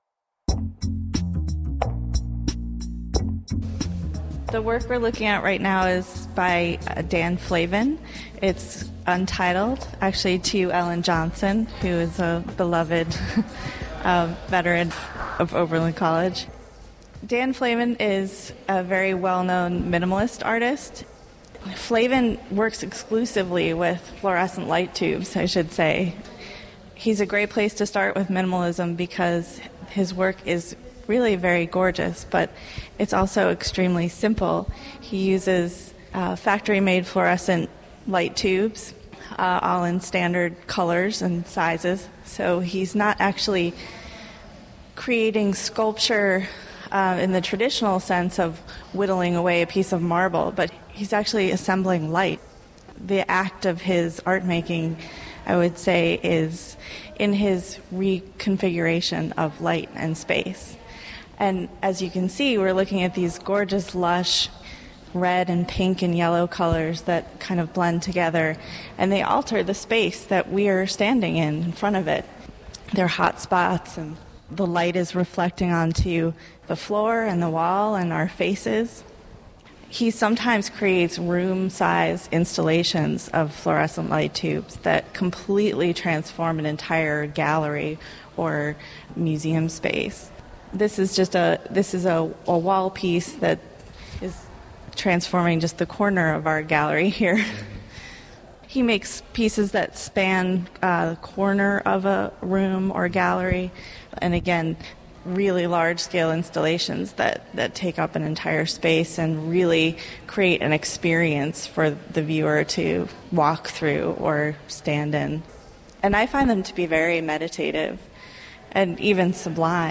These podcasts are taken from conversations in the Akron galleries